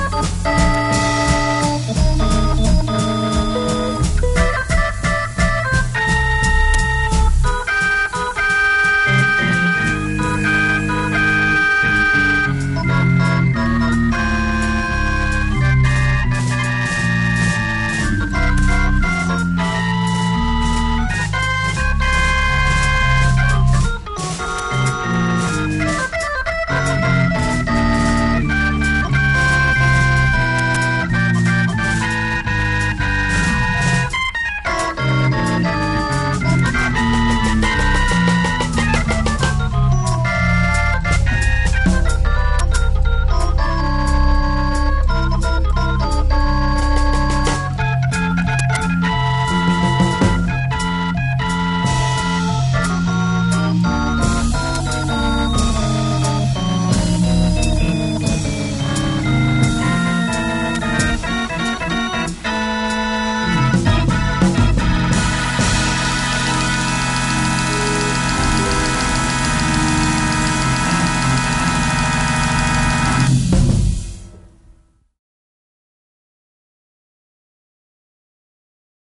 Et dans le 2éme, il prennais des risques !!